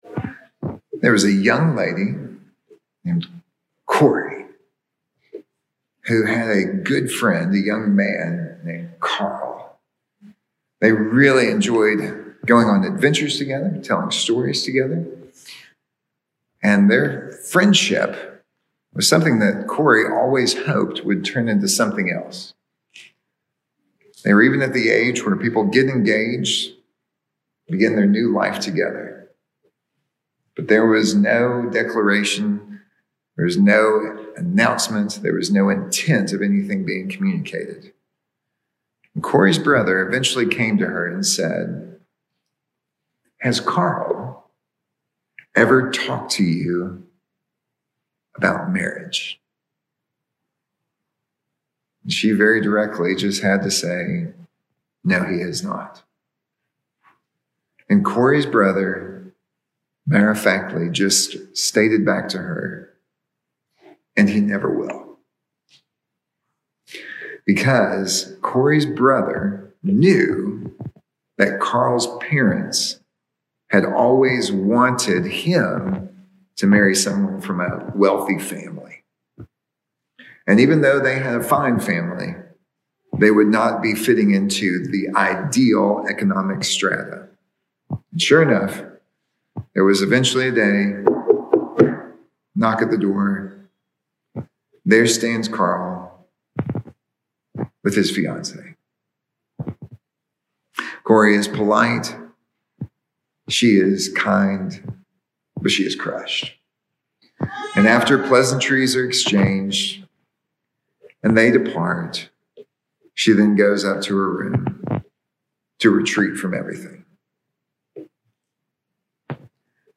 Sermons | Covenant Presbyterian Church